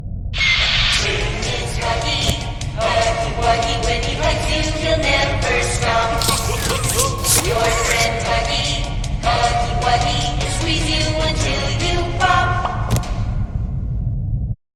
Оригинальная песня